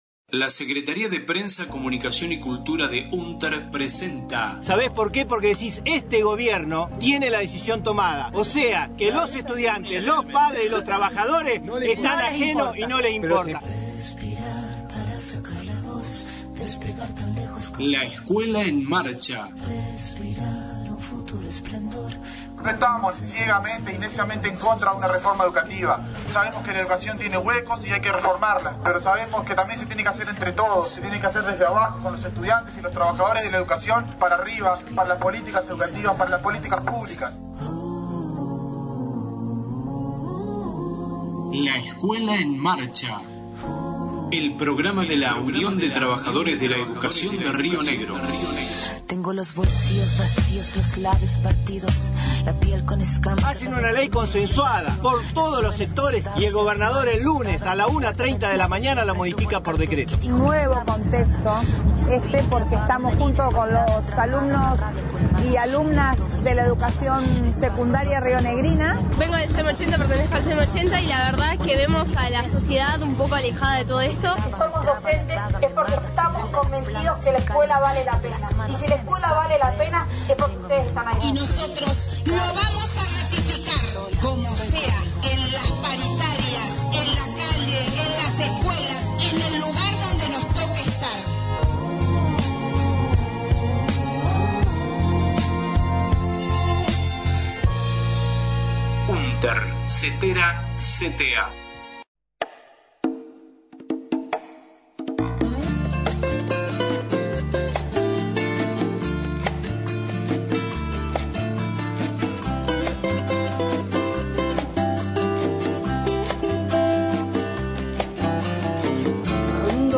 Voces de representantes de listas para elecciones de UnTER el 2/10/19.